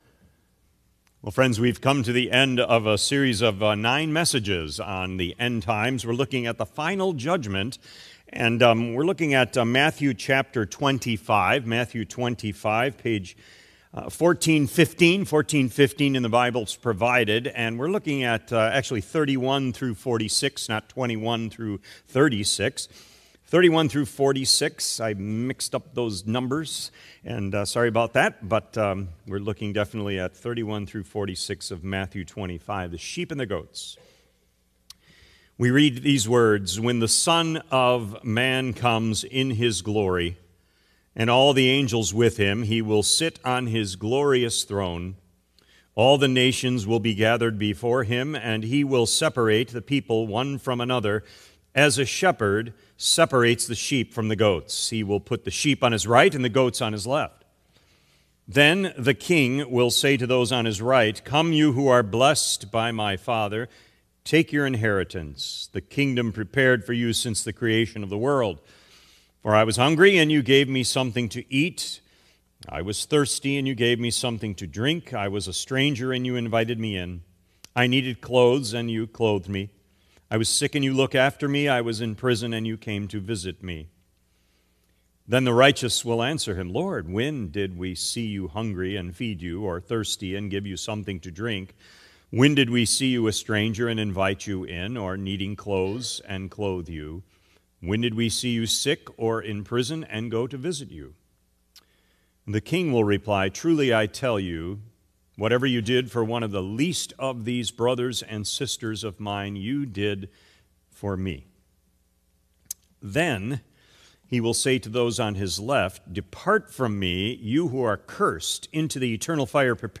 Sermon Recordings | Faith Community Christian Reformed Church
“The Final Judgment” October 12 2025 P.M. Service